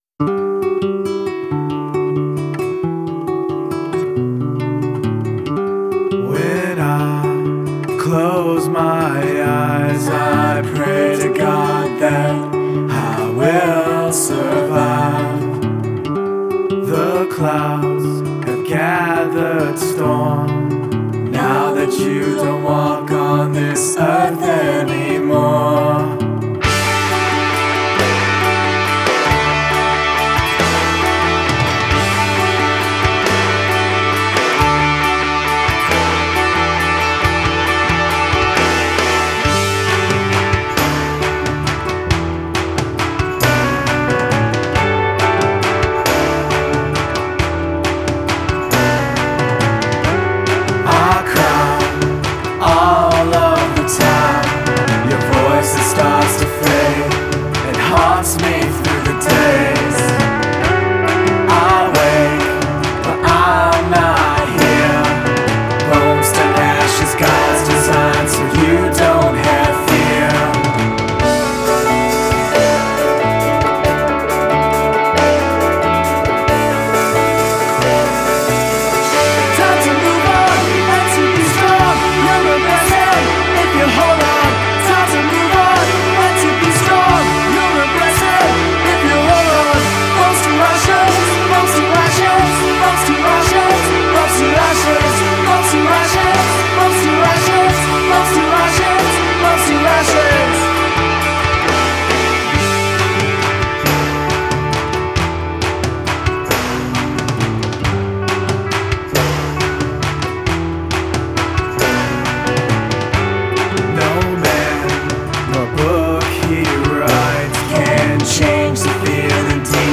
alternative country